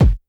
Kick_47.wav